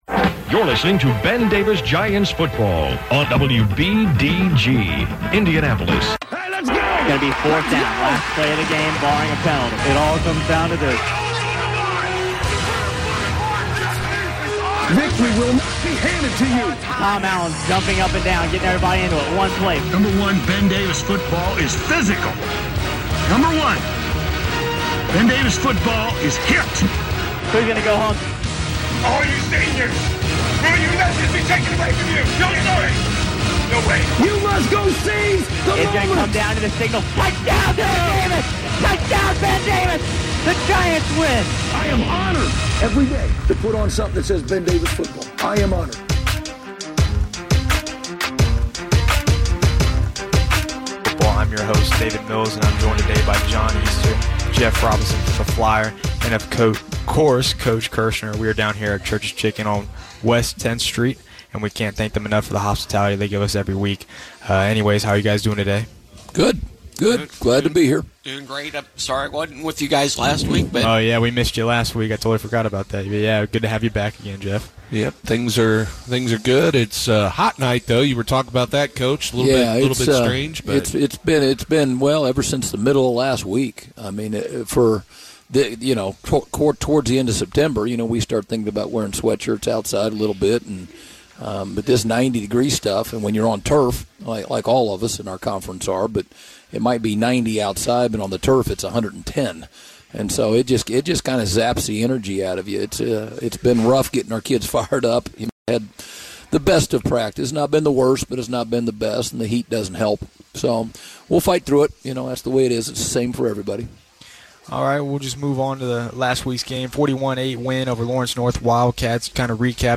Recorded live on September 26 at Church's Chicken on 10th Street.